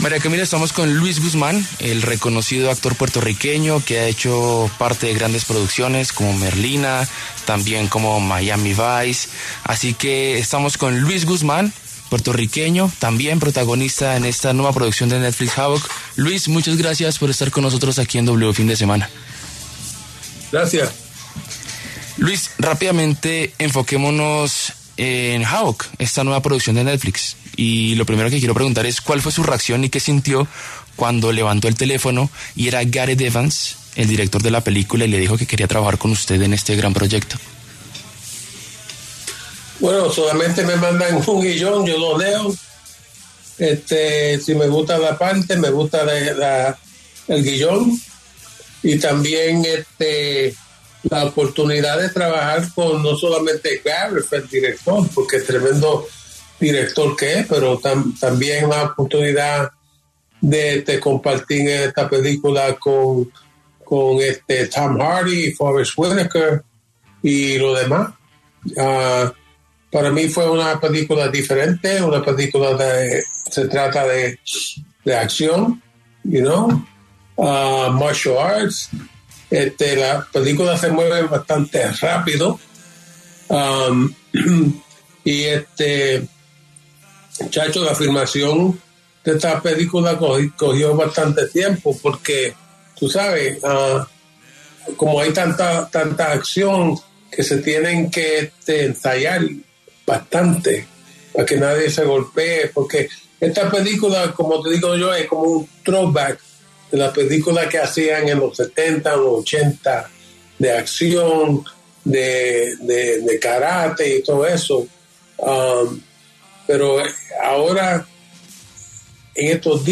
El actor puertorriqueño Luis Guzmán pasó por los micrófonos de W Fin De Semana para hablar de la nueva película de Netflix, ‘Havoc’.